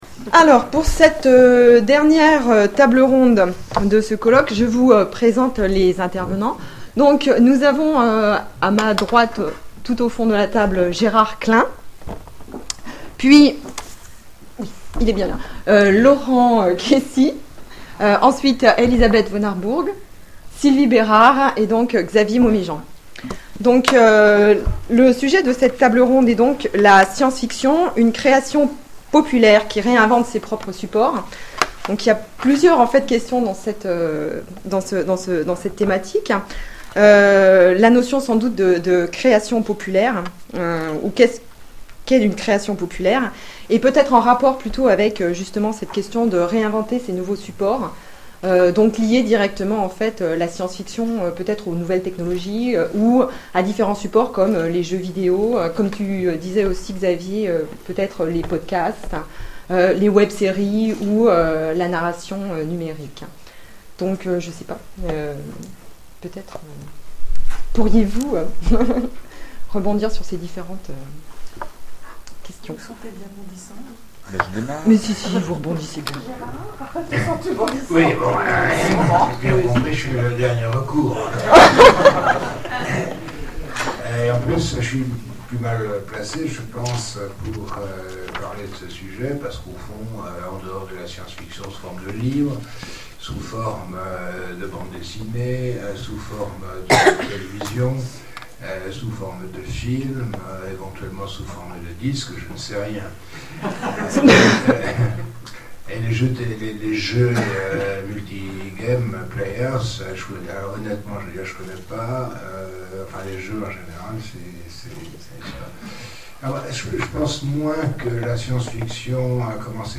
Colloque SF francophone : Table ronde La science-fiction, une création populaire qui réinvente ses propres supports ?
tablerondeauteurquestions.mp3